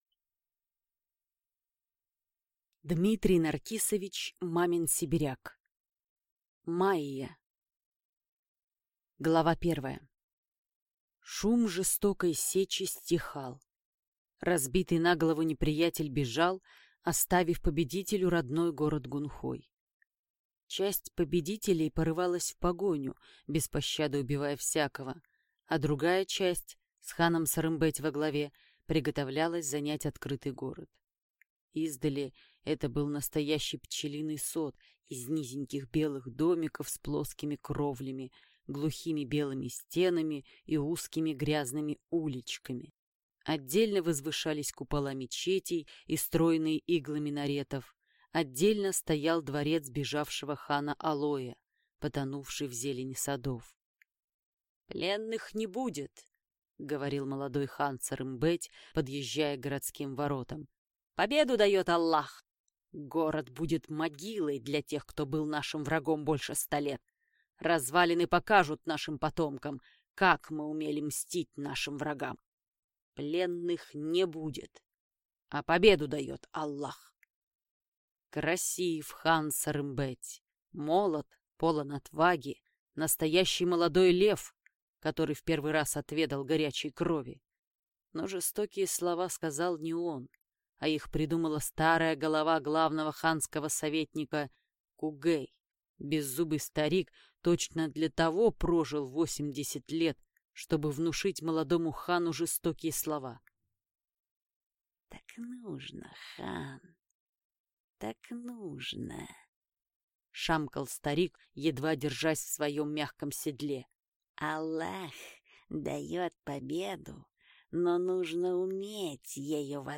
Aудиокнига Майя